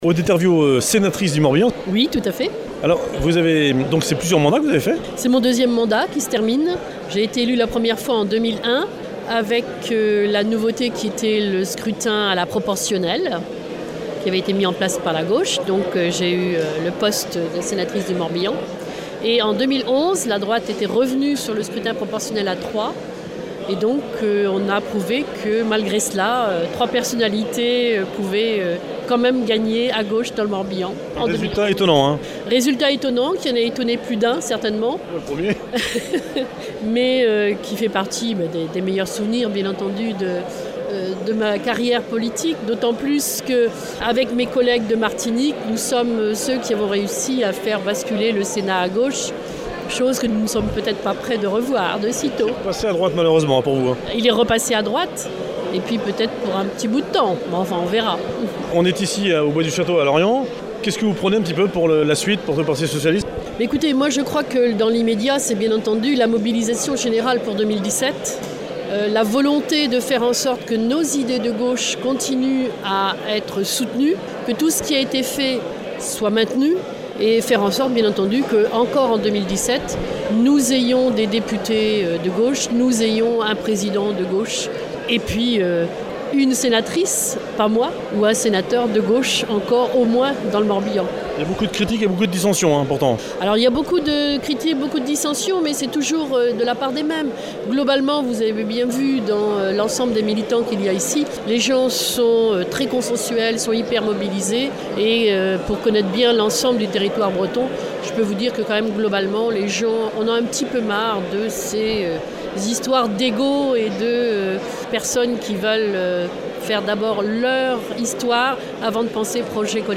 POLITIQUE : La Rentrée 2016-2017 du Parti Socialiste (P.S.) dans le Morbihan à Lorient – Interviews de Gwendal Rouillard et d’Odette Herviaux
C’était la rentrée pour le P.S. samedi 3 septembre dans le Morbihan à Lorient au gymnase du Bois du Château.